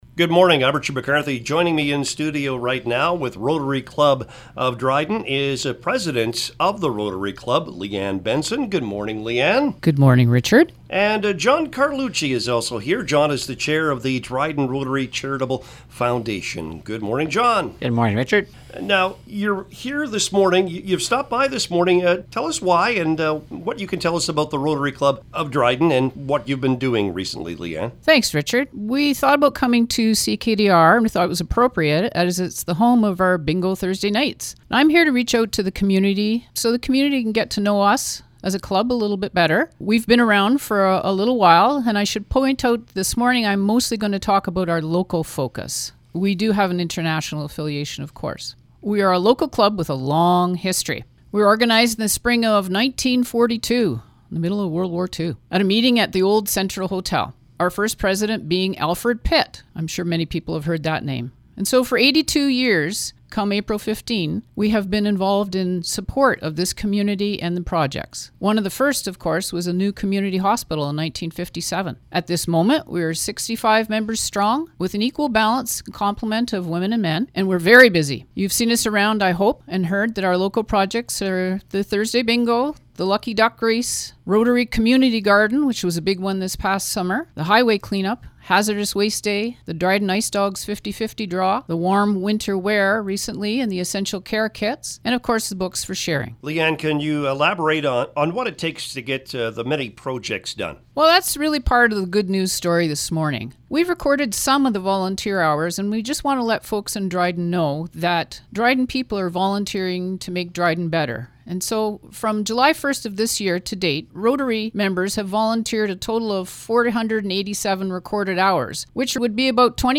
2023-dryden-rotary-interview.mp3